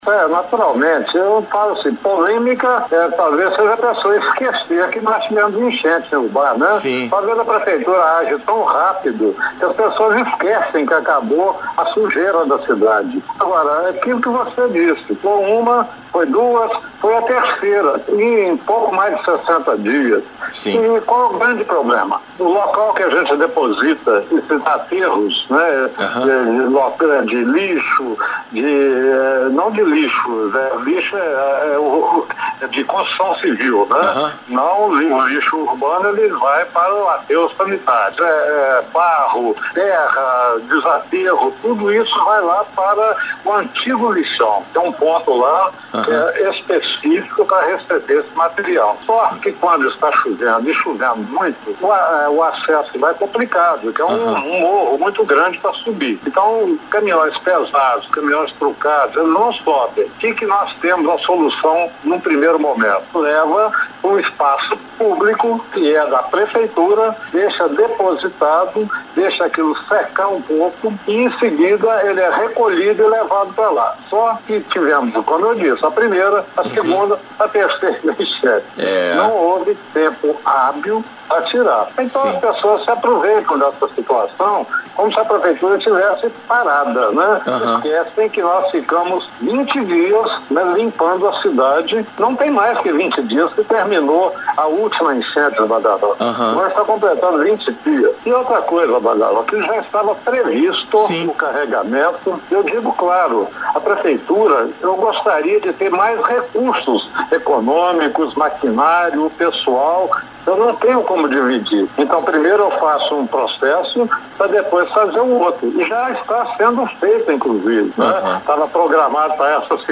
Em entrevista ao jornal Conexão Líder da Rádio Líder FM, no início da tarde desta segunda-feira(27/04), o Prefeito Edson Teixeira Filho, falou de um vídeo que circulou nas redes sociais no último fim de semana, onde supostamente a Prefeitura teria transformado o espaço do Horto Florestal em um lixão.